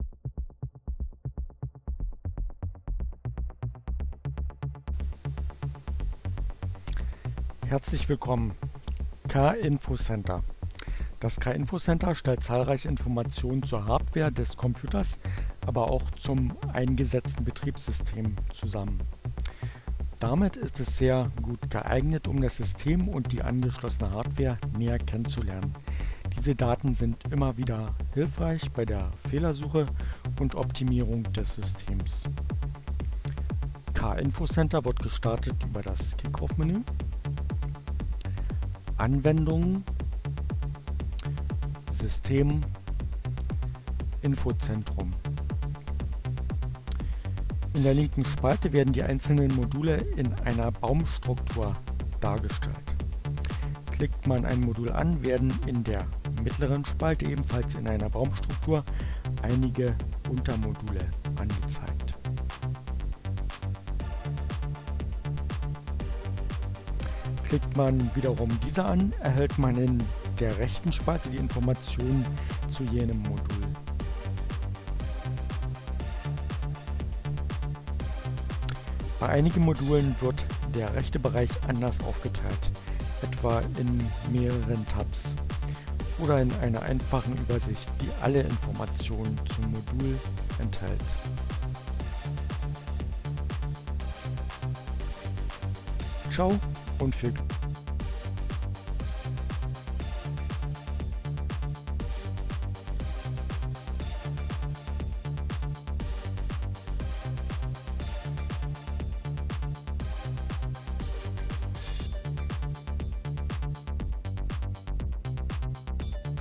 Tags: CC by-sa, KDE, Linux, Neueinsteiger, Ogg Theora, ohne Musik, screencast, Kubuntu, KDE SC, kinfocenter